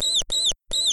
sounds_mouse.ogg